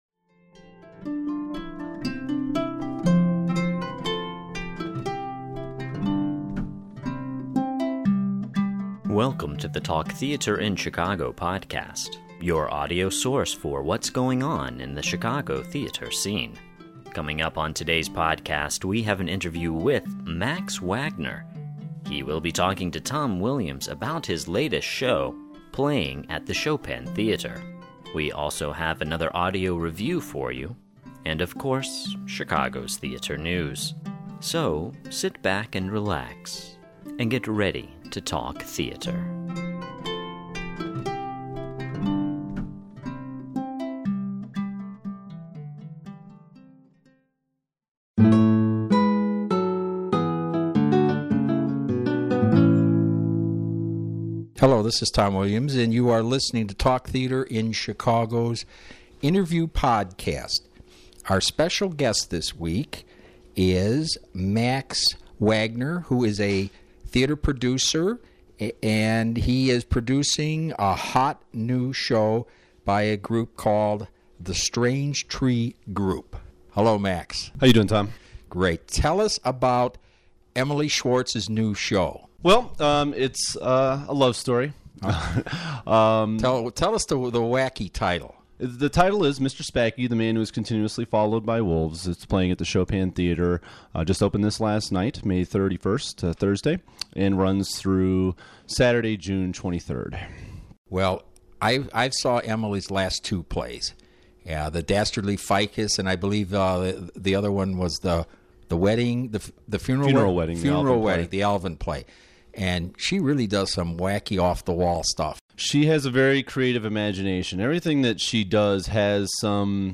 Interview Podcast